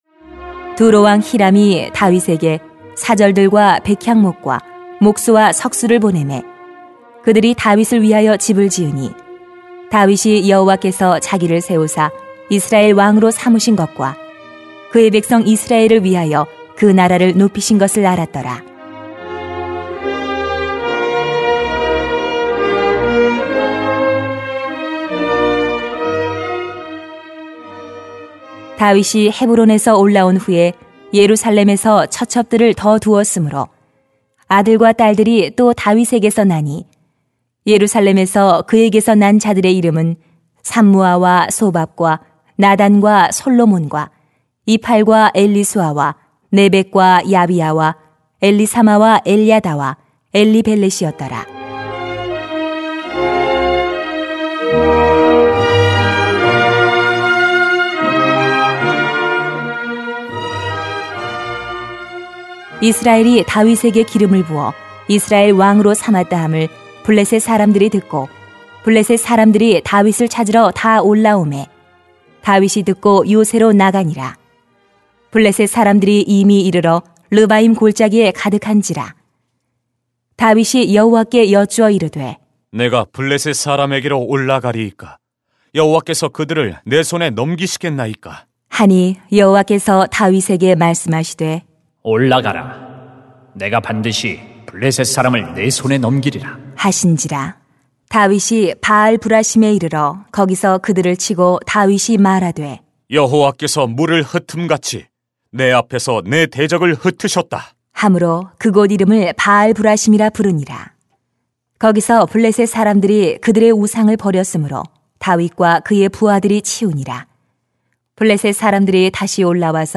[삼하 5:11-25] 하나님이 함께 하시는 증거만 있으면 됩니다 > 새벽기도회 | 전주제자교회